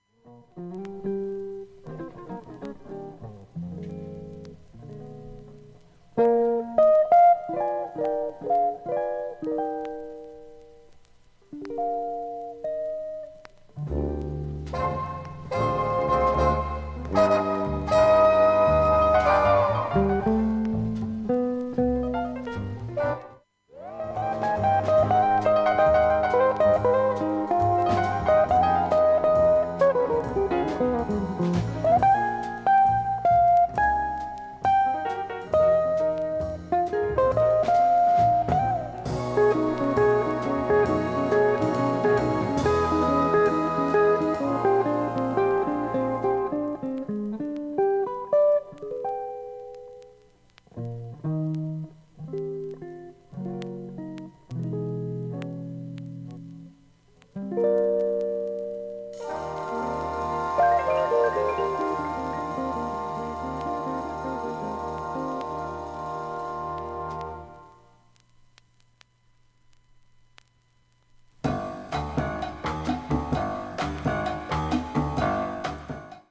普通に聴けます音質良好全曲試聴済み。
A-2始めにかすかなプツが4回と6回出ます
プツ出ますが曲間以外聴こえないレベルです。